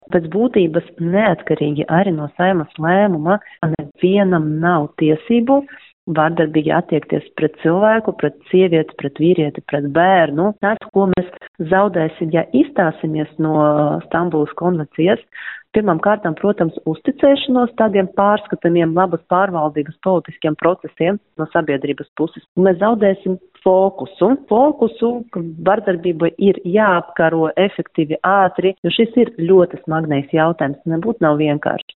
Vērtējot daļas politiķu rosinājumu izstāties no tā dēvētās Stambulas konvencijas, tiesībsardze Karina Palkova intervijā Skonto mediju grupai sacīja, ka šie starptautiskie dokumenti pēc būtības, valstij uzdod darba uzdevumus, arvien uzlabot cietušo tiesību aizsardzības mehānismus.